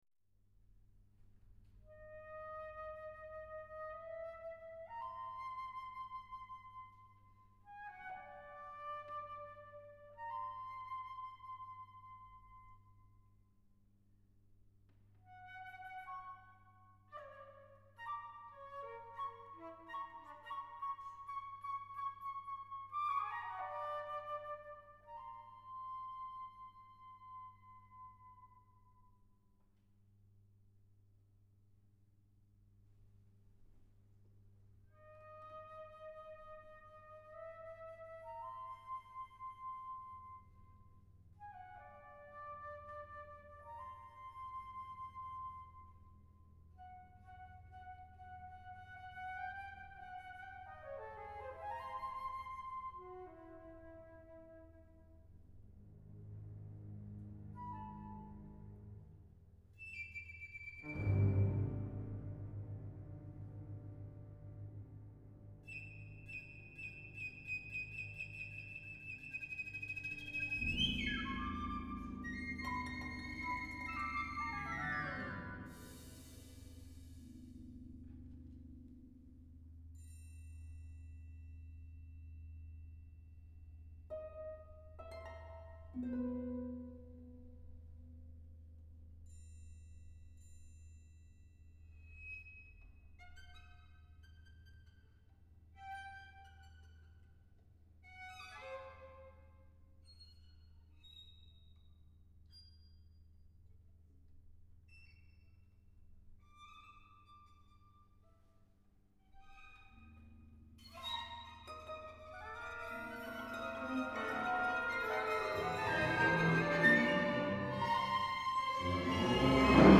for orchestra